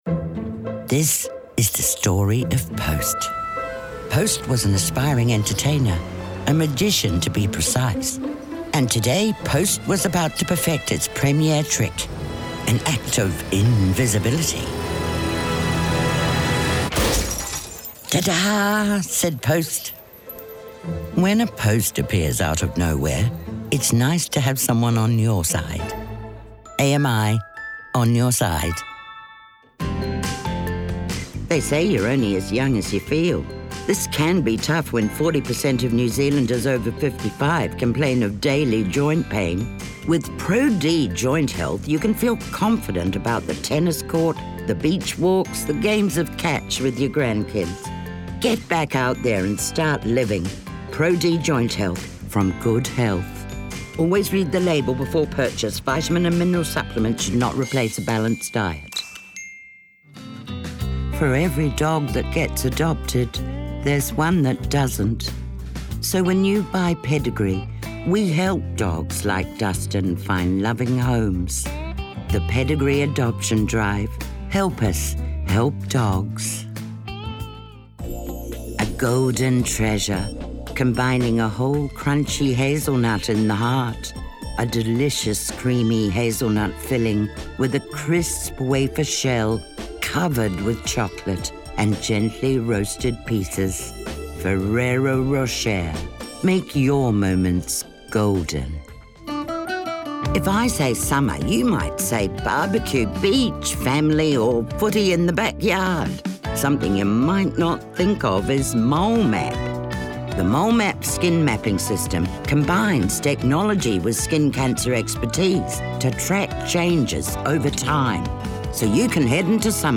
Demo
Adult, Mature Adult
international english
new zealand | natural
COMMERCIAL 💸
husky